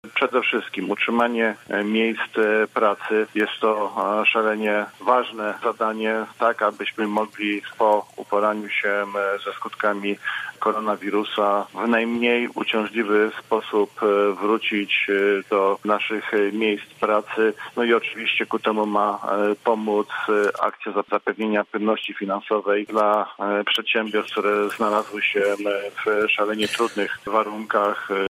Zdaniem porannego gościa Radia Zachód najbardziej narażone na kryzys są obecnie mikro i małe przedsiębiorstwa: